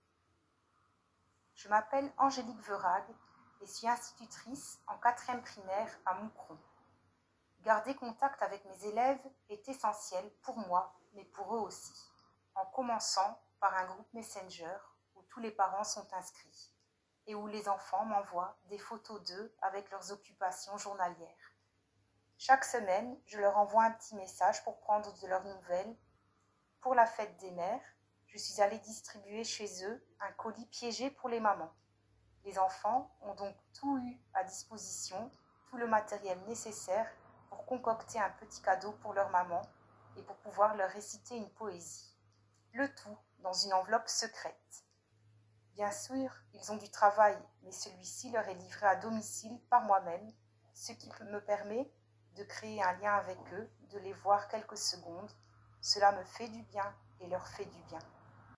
Des témoignages audio de professionnels de l’enseignement